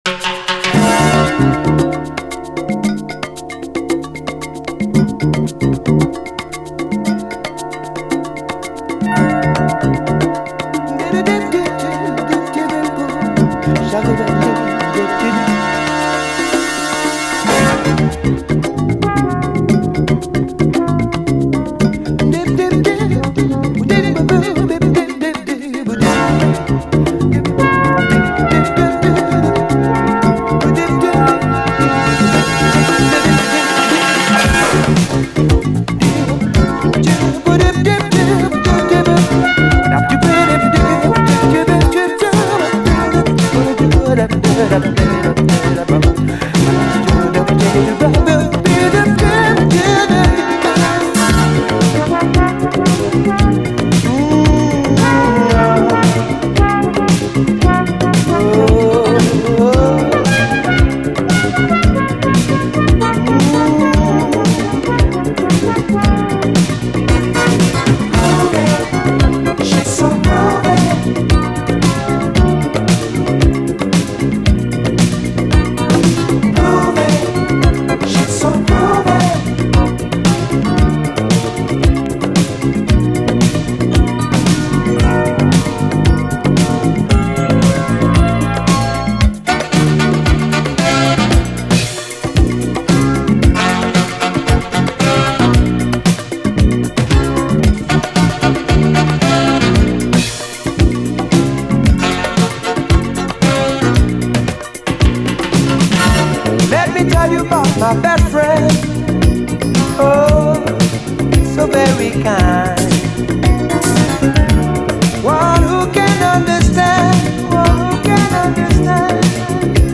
SOUL, 70's～ SOUL, DISCO
バレアリック＆トロピカルな84年イタリア産爽快モダン・ブギー！